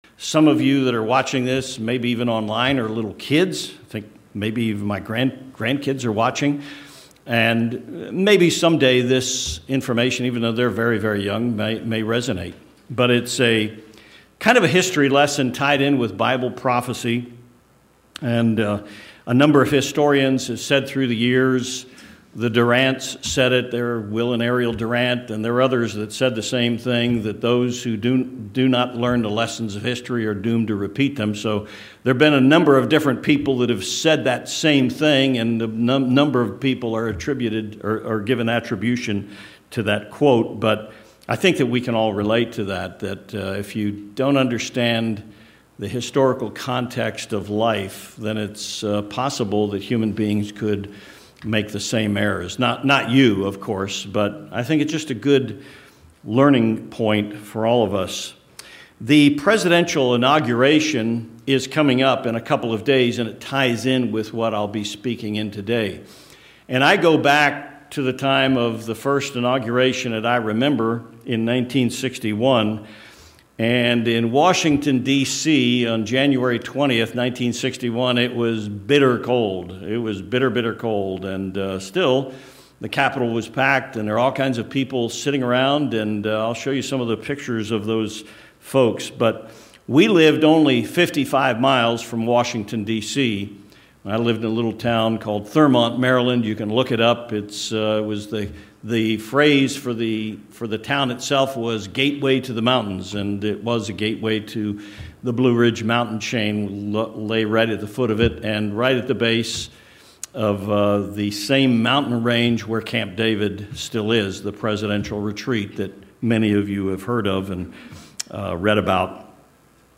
Sermons
Given in Tucson, AZ El Paso, TX